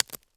satisfyingClick.wav